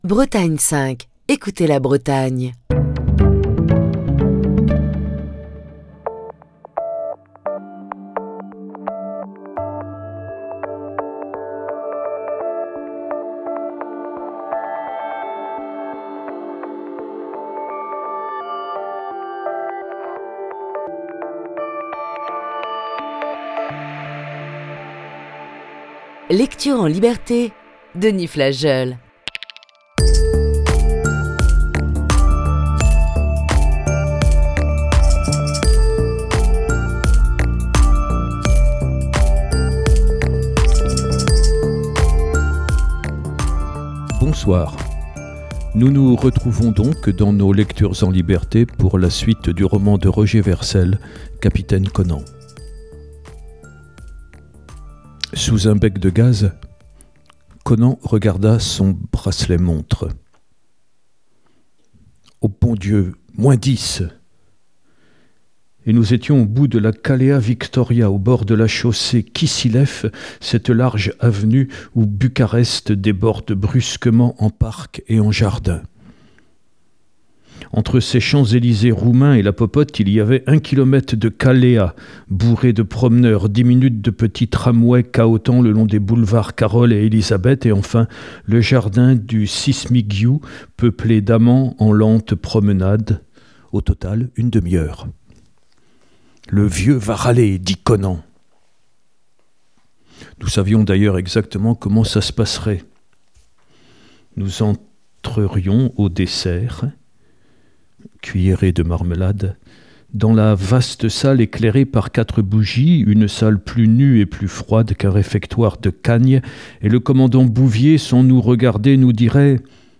lit le roman